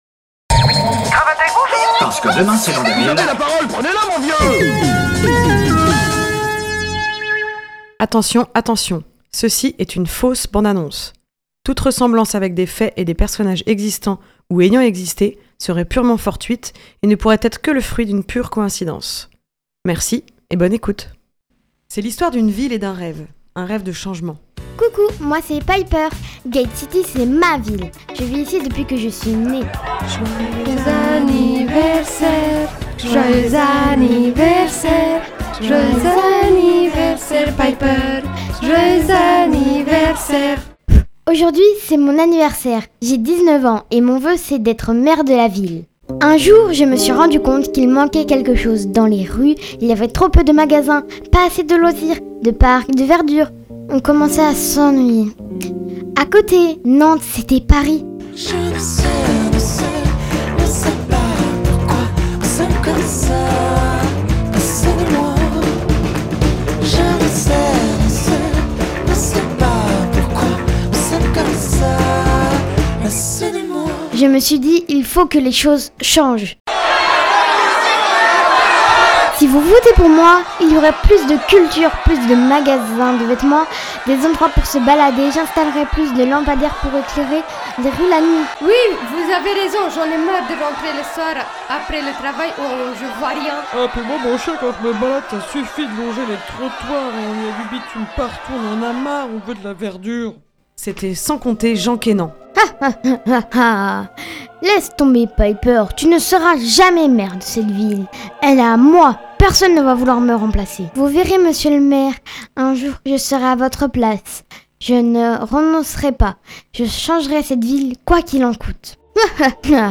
Fausse bande annonce